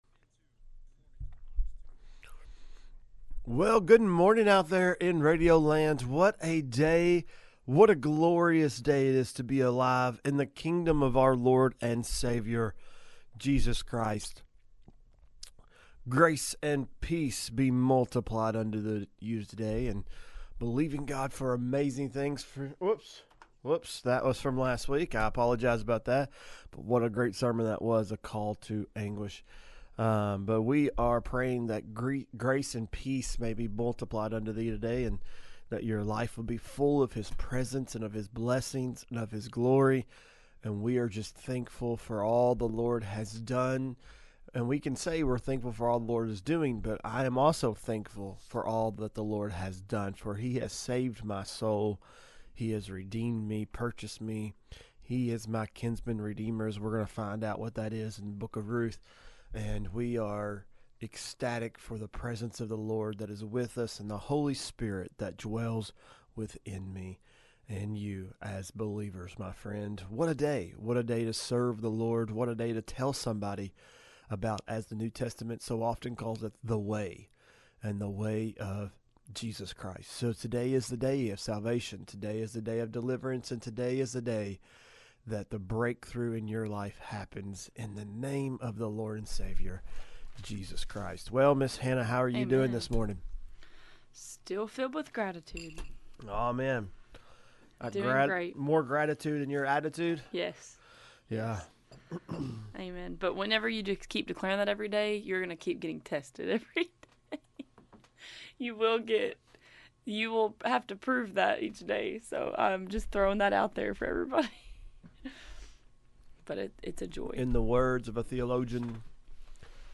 MBR: Naomi’s Role in the book of Ruth and a Conversation on Apostasy and the End Times – Box 2 Radio Network